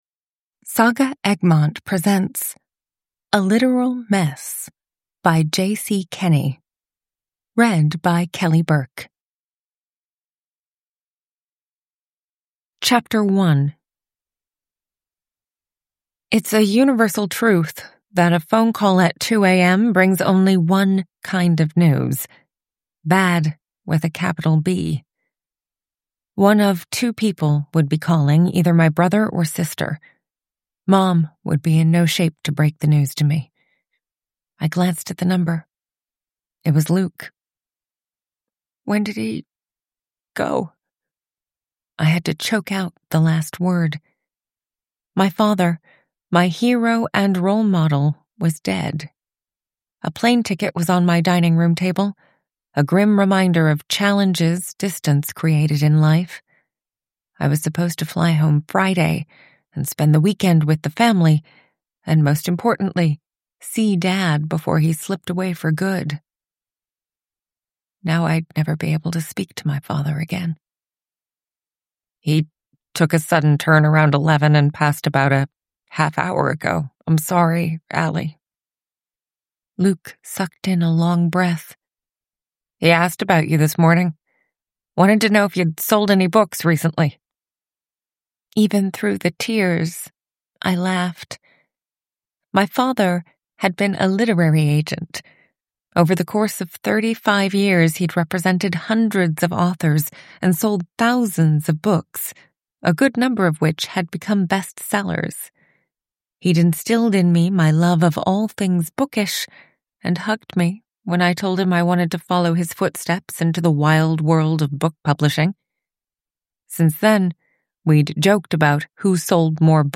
A Literal Mess: A bookish, small town, cozy mystery with a literary agent sleuth (ljudbok) av J. C. Kenney